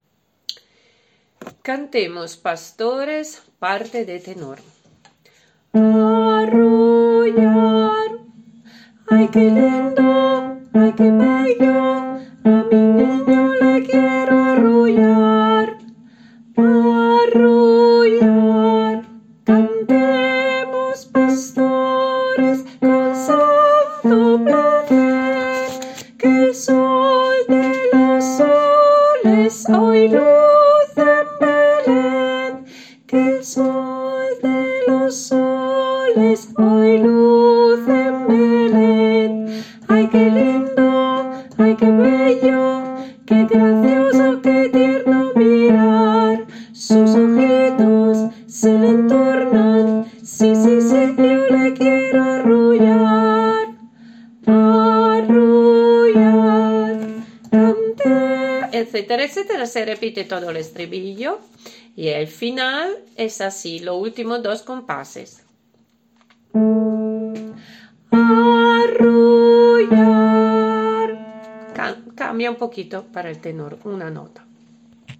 TENOR